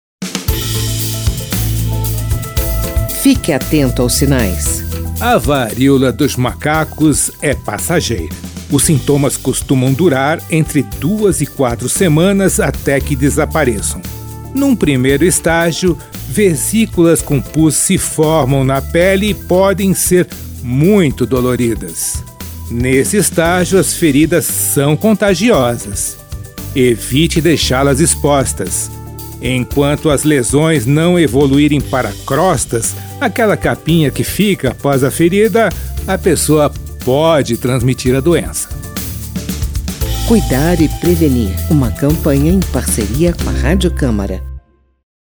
Texto e locução